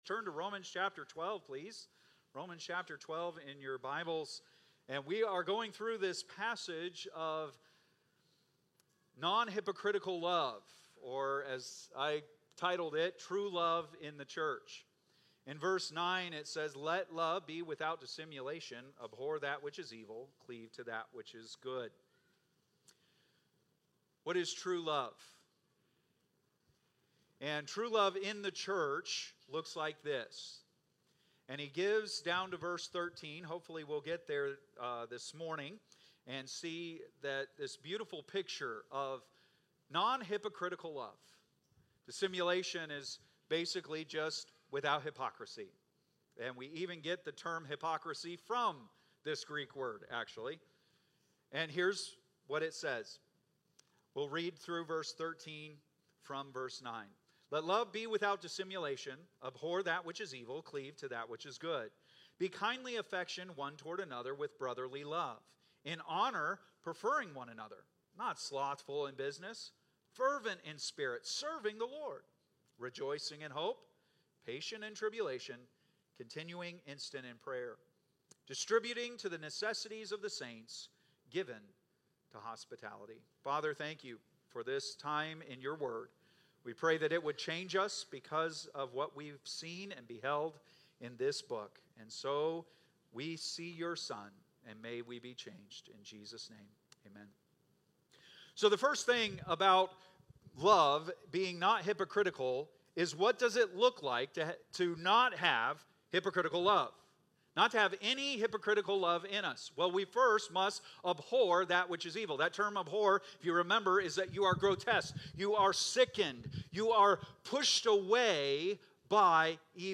Date: July 20, 2025 (Sunday Morning)